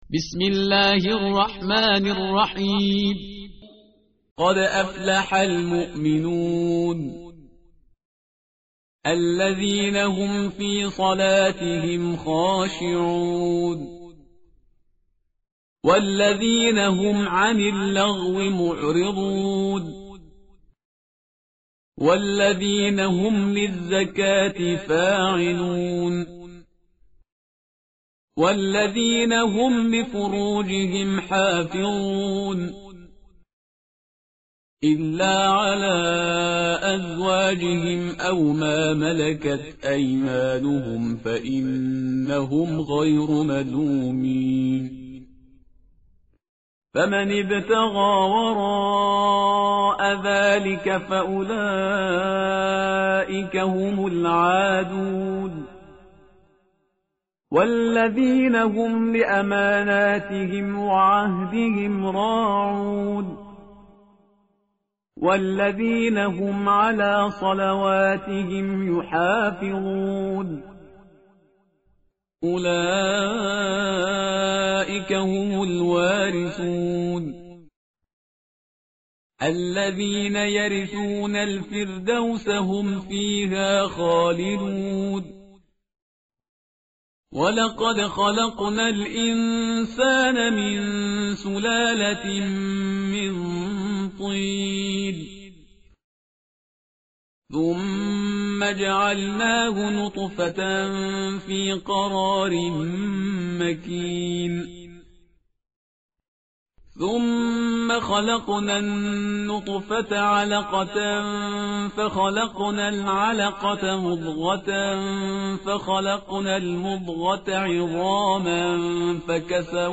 tartil_parhizgar_page_342.mp3